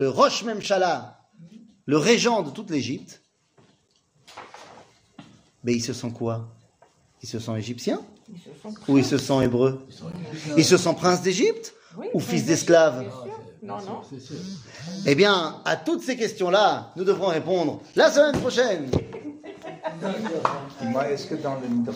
שיעור מ 25 אוקטובר 2023